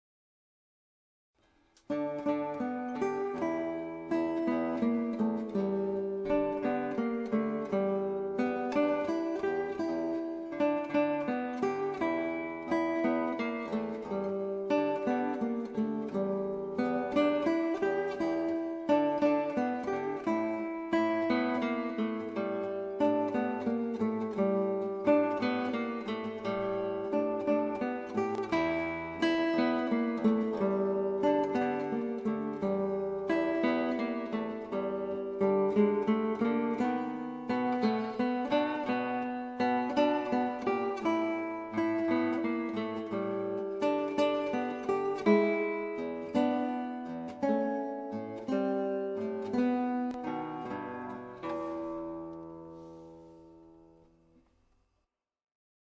ANVULLENDE GITAARPARTIJ
• 2 gitaren
• Thema: duet